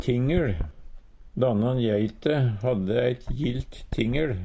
Høyr på uttala Ordklasse: Substantiv inkjekjønn Kategori: Jordbruk og seterbruk Attende til søk